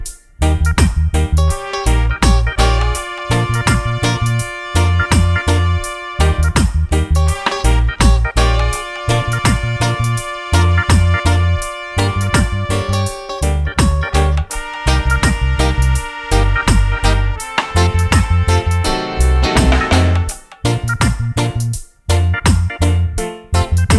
no Backing Vocals Reggae 3:49 Buy £1.50